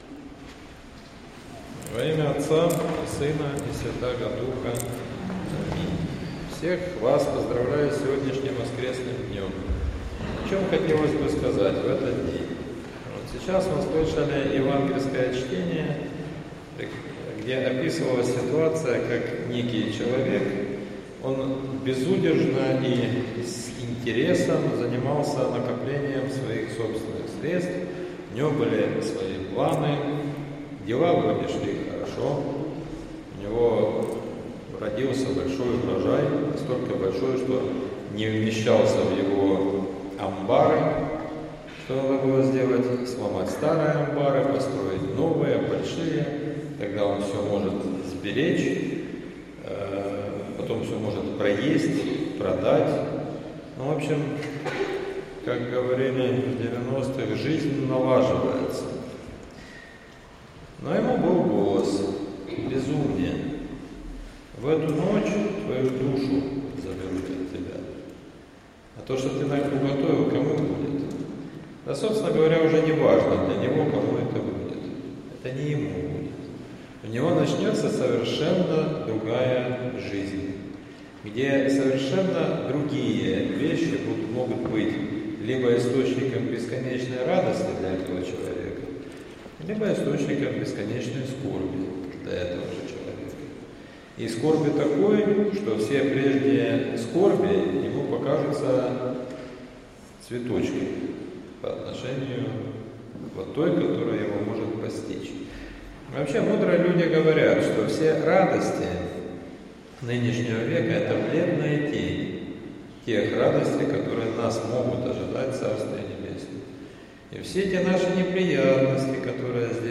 Проповедь в двадцать пятую неделю по Пятидесятнице — Спасо-Преображенский мужской монастырь
В воскресенье, 30 ноября, в двадцать пятую неделю по Пятидесятнице, на Божественной Литургии читался отрывок из Евангелия от Луки(12:16-21).